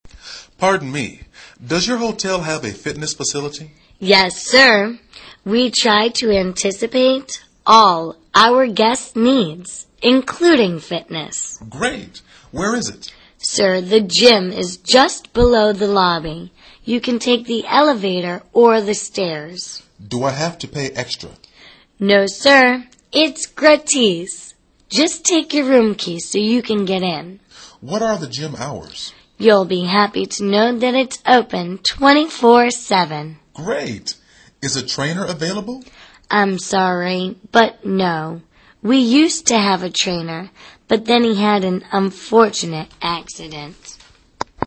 旅馆英语对话-Hotel Gym(2) 听力文件下载—在线英语听力室